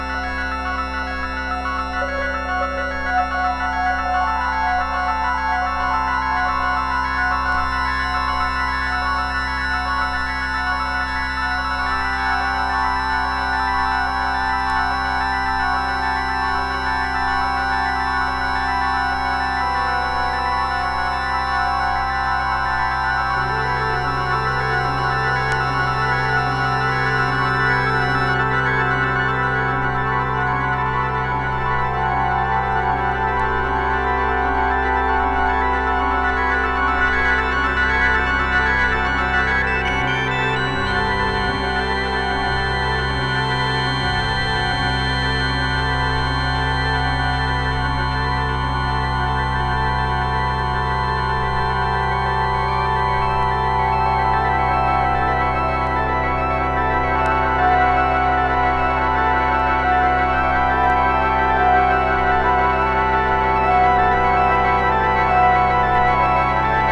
体中のコリがほぐれそうなメディテーション・サウンド。